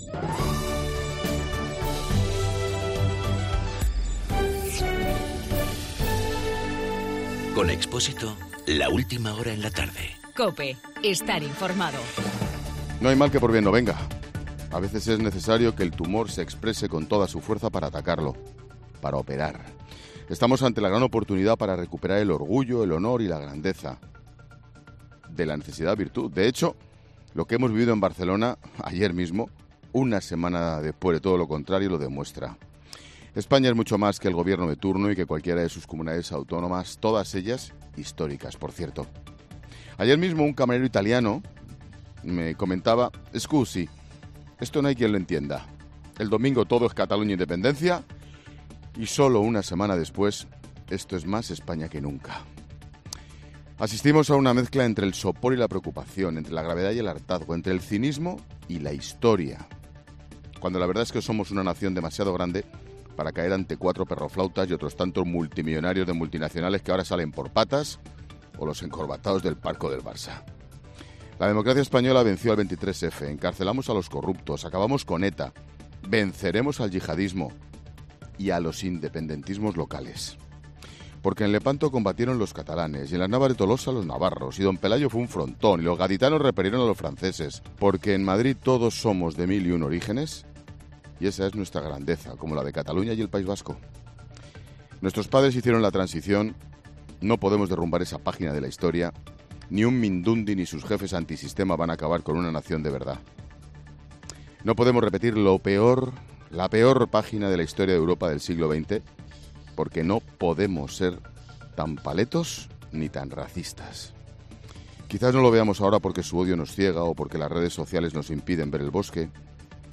AUDIO: Ángel Expósito desde Barcelona.
Monólogo de Expósito